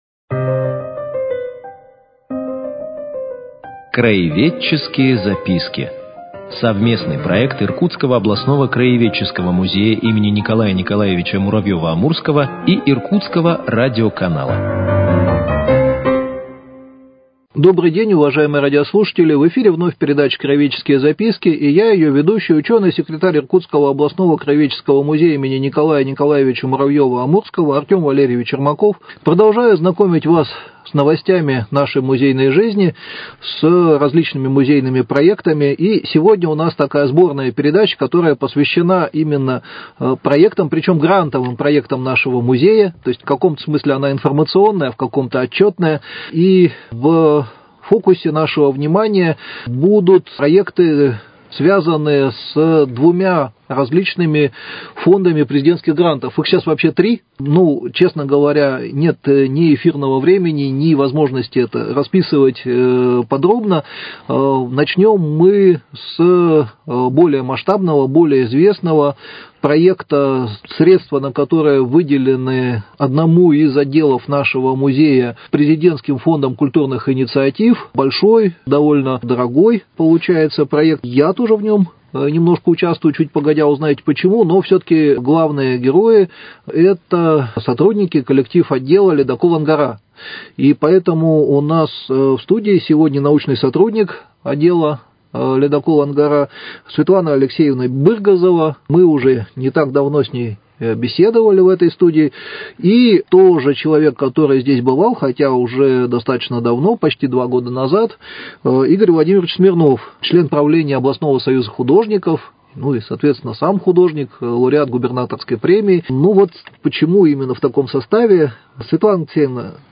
Мы предлагаем вниманию слушателей цикл передач – совместный проект Иркутского радиоканала и Иркутского областного краеведческого музея.